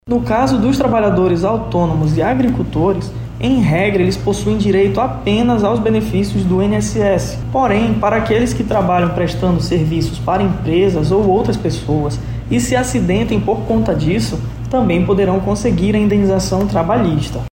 O advogado trabalhista e previdenciário